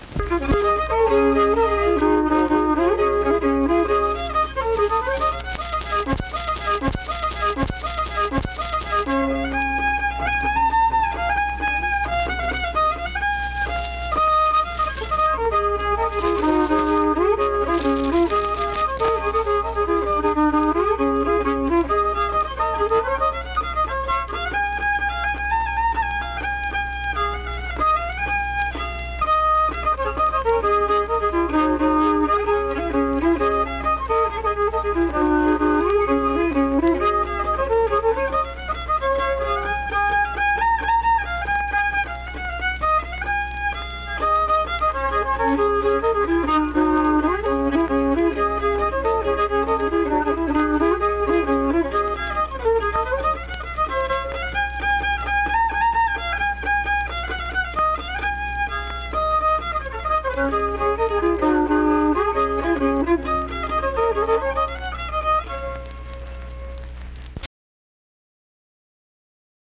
Instrumental fiddle performance.
Instrumental Music
Fiddle
Wood County (W. Va.), Vienna (W. Va.)